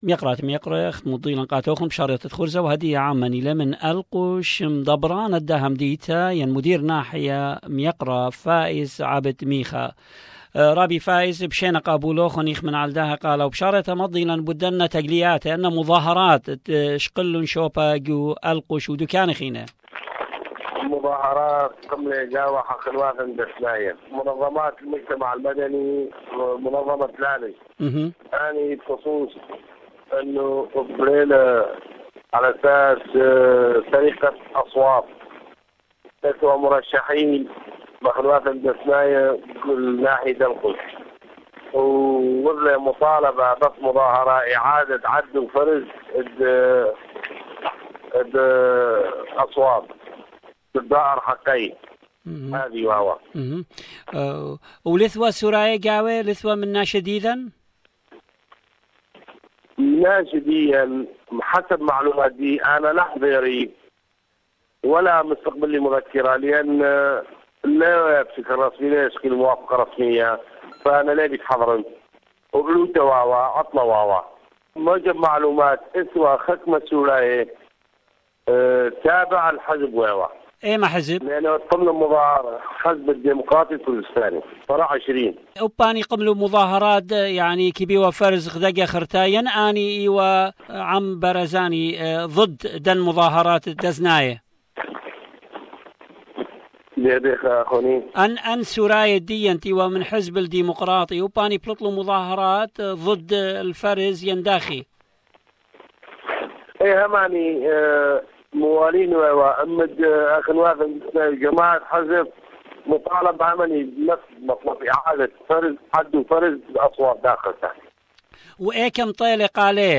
Mr Faiz Abid Micha, Mayor of Alqosh speaks to SBS Radio about the latest demonstrations and protest against election Fraud in Alqosh.